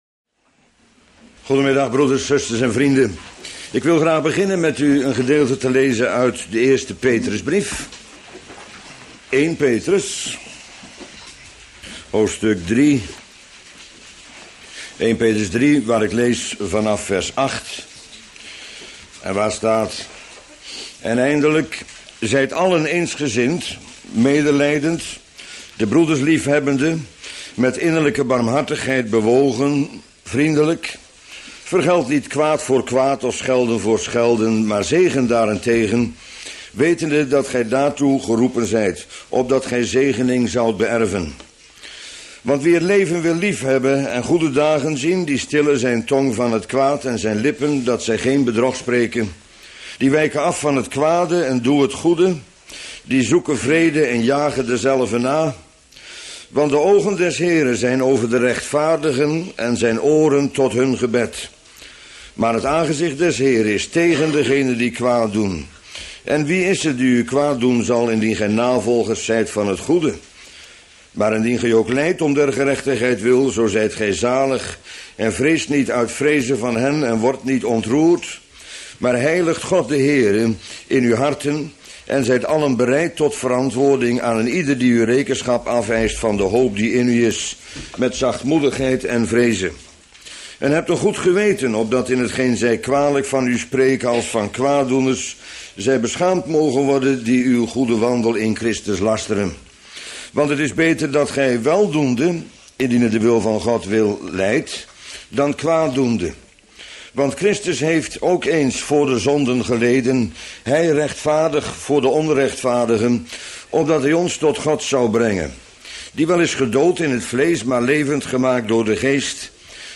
Bijbelstudie lezing